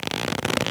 foley_leather_stretch_couch_chair_10.wav